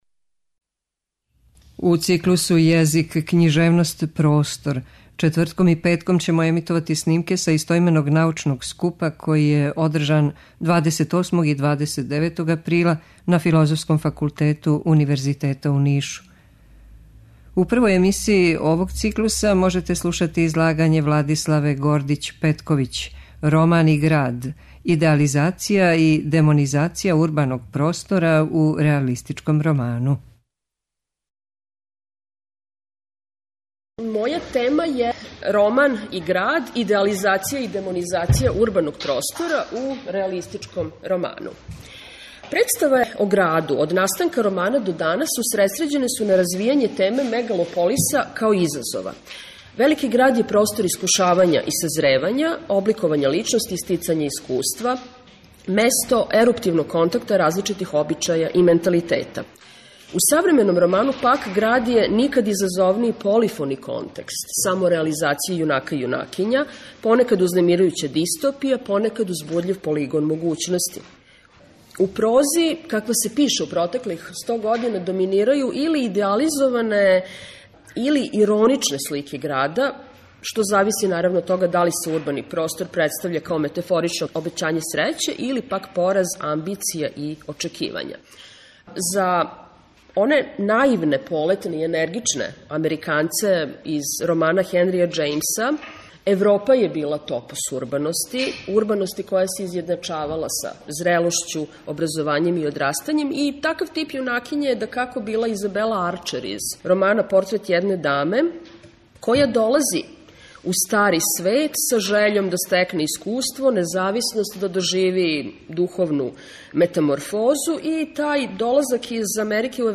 У циклусу ЈЕЗИК, КЊИЖЕВНОСТ, ПРОСТОР четвртком и петком ћемо емитовати снимке са истоименог научног скупа, који је ордржан 28. и 29. априла на Филозофском факултету Универзитета у Нишу.
Научни скупoви